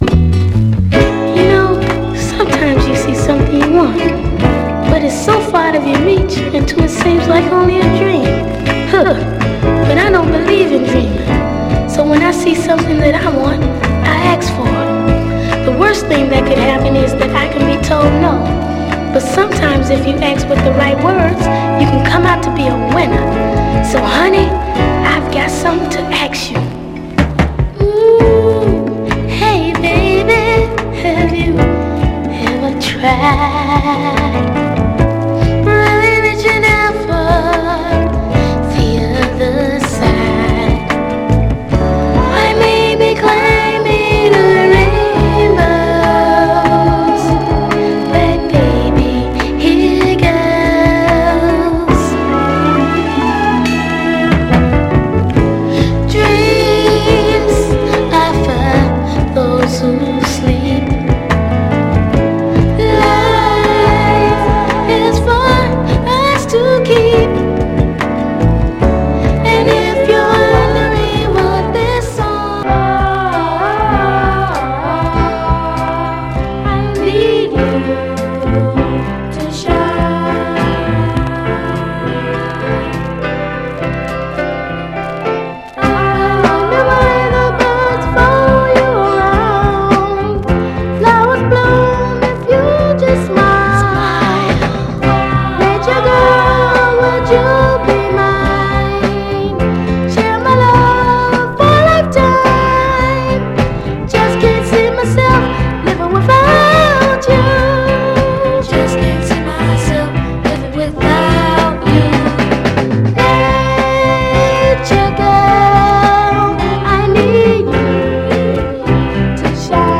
序盤で大きめにチリつく箇所あります。ただしそれ以降はグロスが残っておりプレイ概ね良好です。
※試聴音源は実際にお送りする商品から録音したものです※